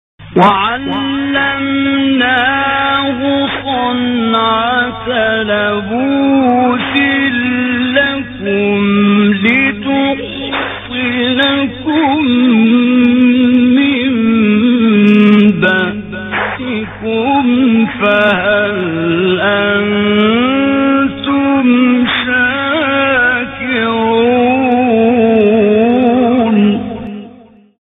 فرازی با صدای حمدی زامل سوره انبیاء | نغمات قرآن | دانلود تلاوت قرآن